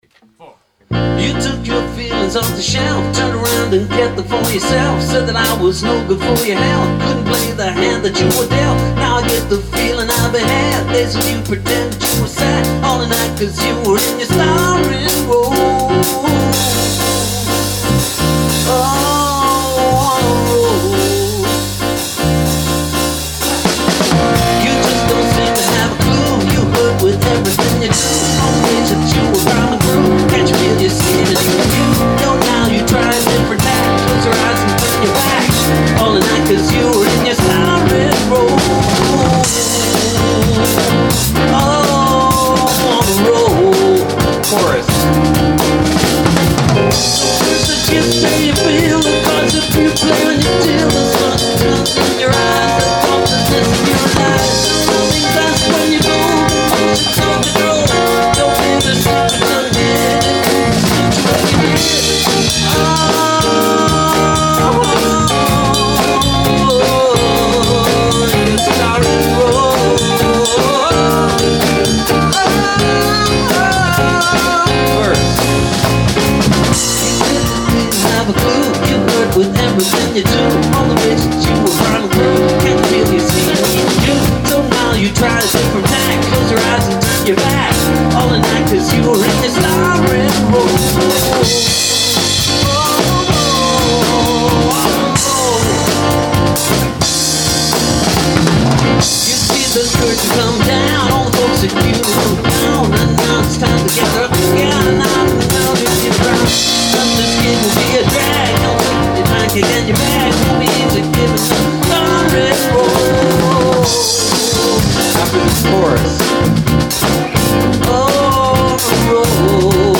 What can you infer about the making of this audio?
bass. Not horrible, could work.